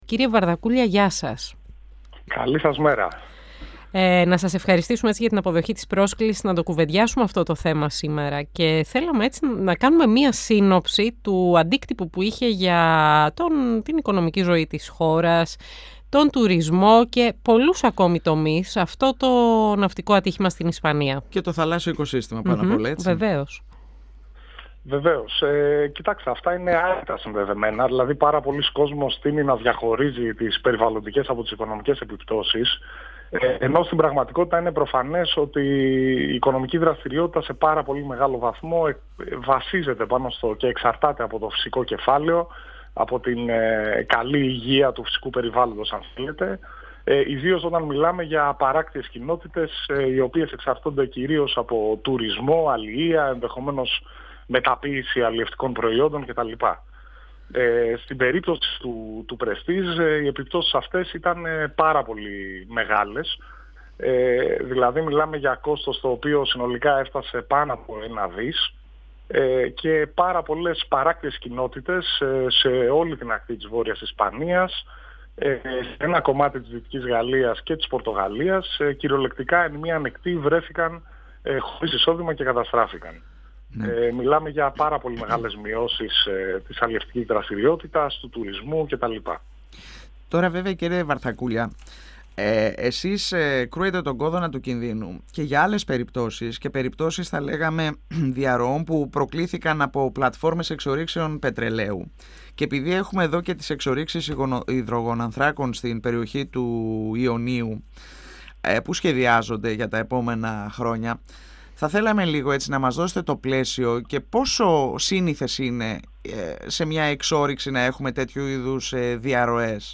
στον 102 fm της ΕΡΤ3